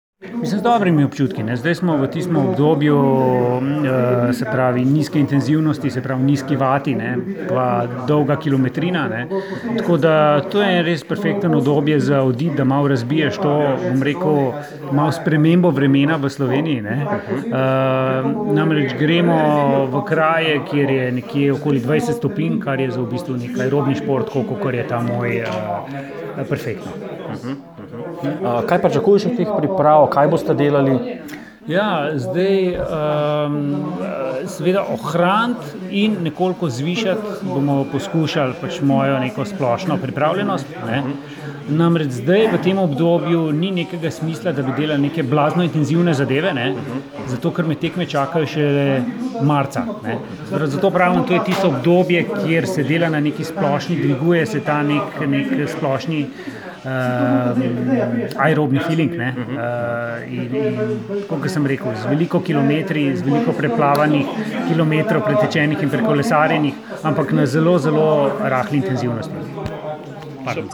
Avdio izjava